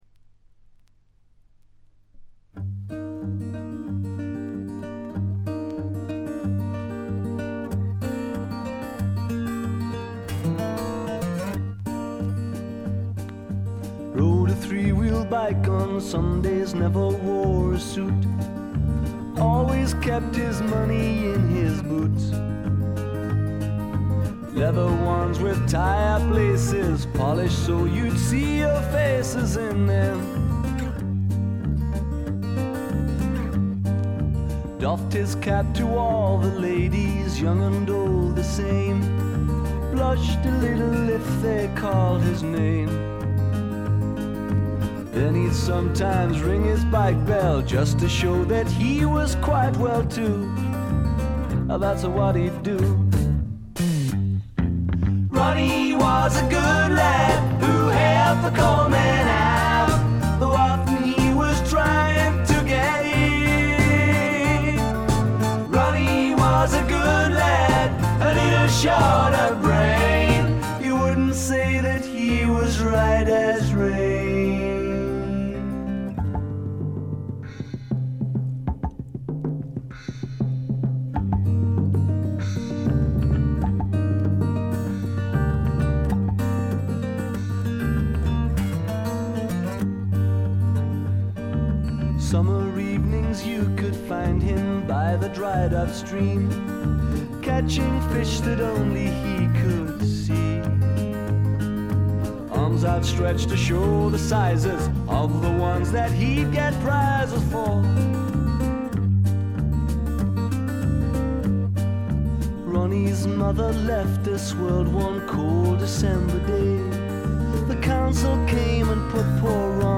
試聴曲は現品からの取り込み音源です。